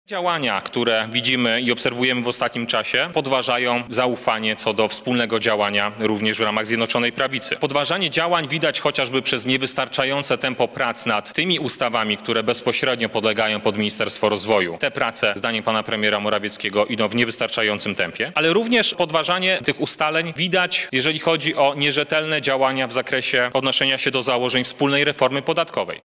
W ostatnim czasie z niepokojem obserwujemy działania wicepremiera Gowina, pomimo tego że podpisał się pod Polskim Ładem – mówi rzecznik Rady Ministrów Piotr Müller: